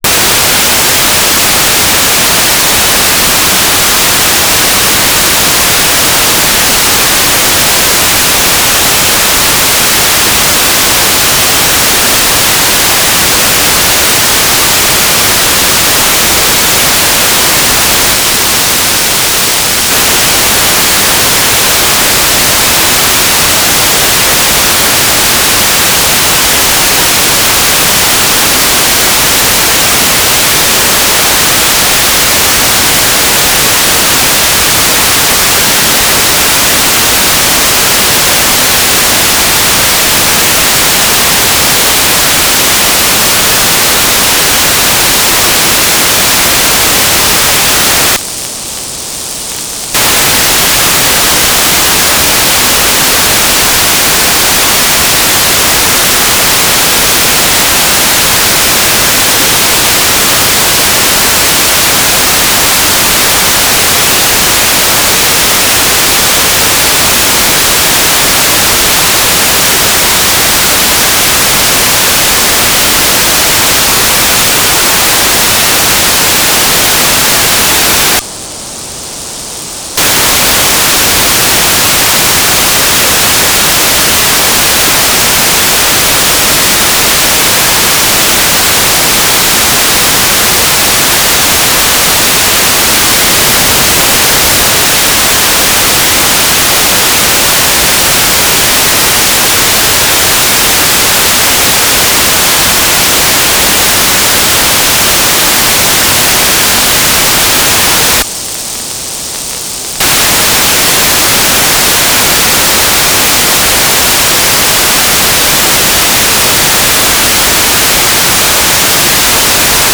"transmitter_description": "Mode U - GMSK2k4 - USP",